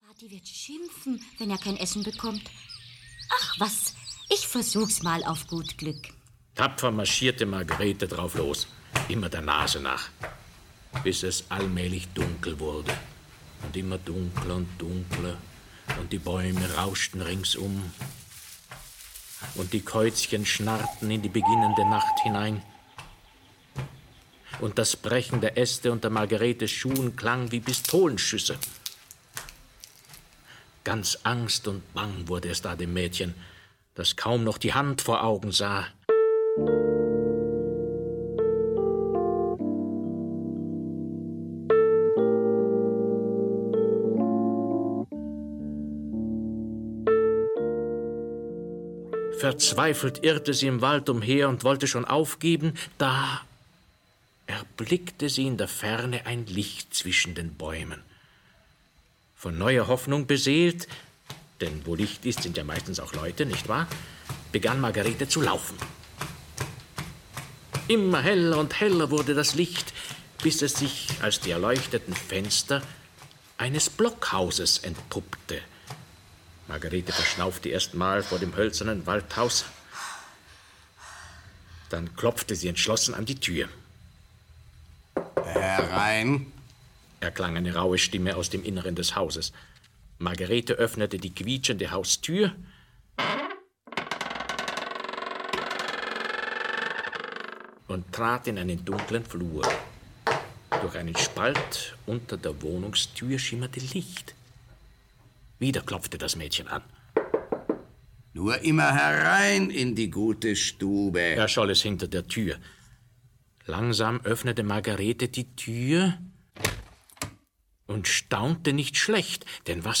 Grimms Märchen - Gebrüder Grimm - Hörbuch